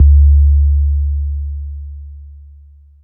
Long 808 (JW3).wav